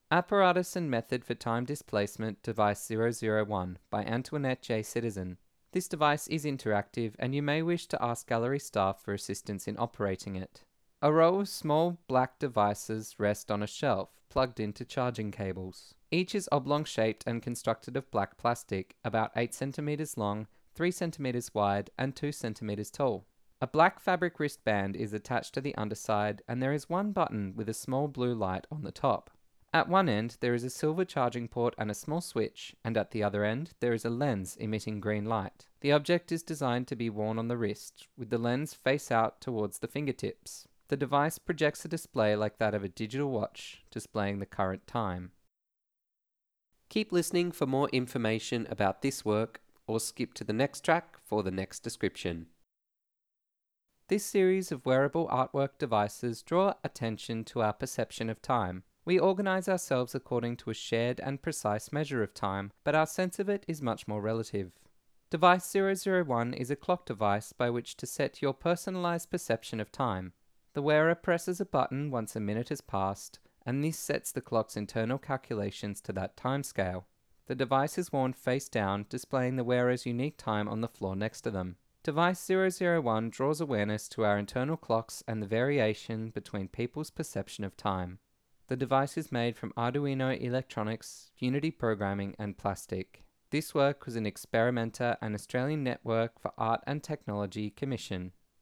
Presented as part of Experimenta Make Sense (2017-2021) Connect Enquiries to present this artwork Resources Audio Guide 'Device001'